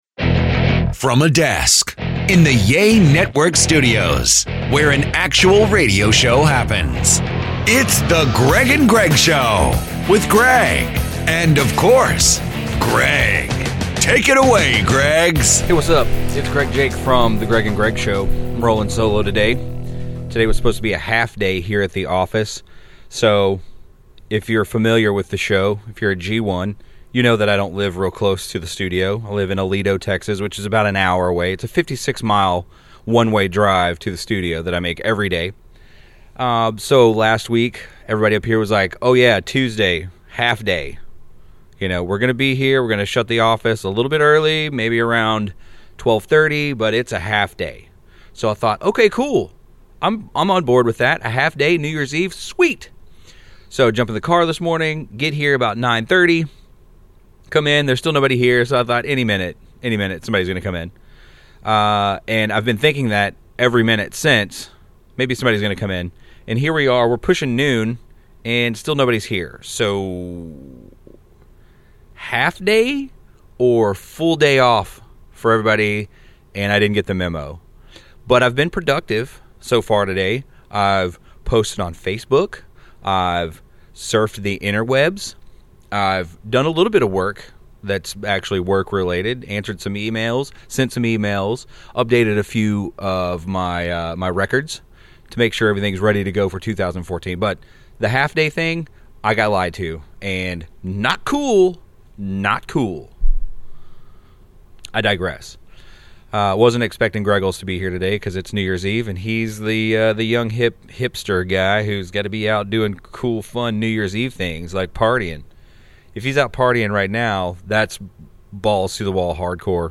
Well, guess what? He was the only one at the studio, so he recorded a little note for you!